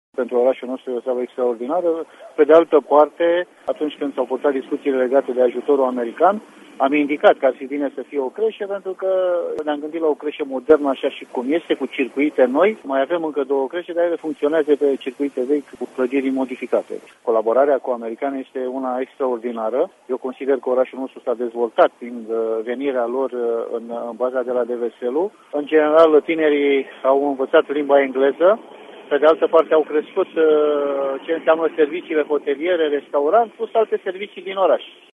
Insert-primar-Caracal-Cresa.mp3